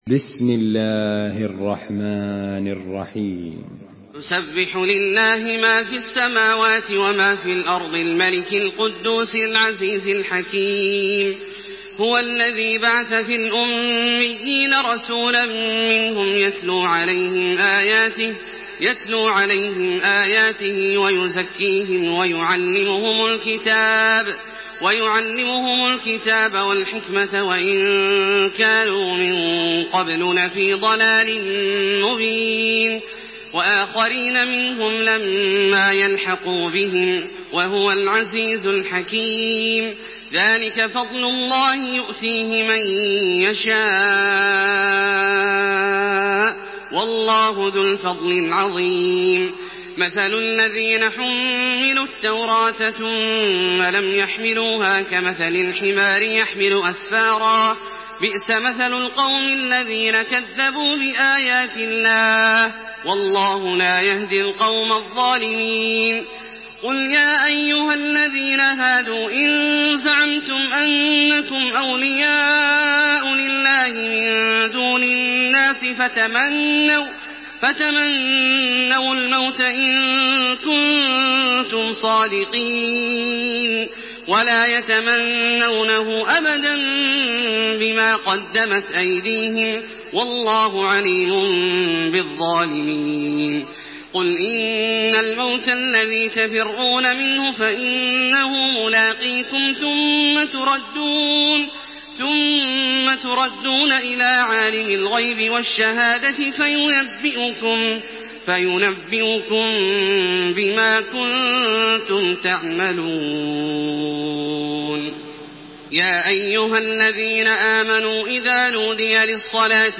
Surah আল-জুমু‘আ MP3 in the Voice of Makkah Taraweeh 1428 in Hafs Narration
Murattal Hafs An Asim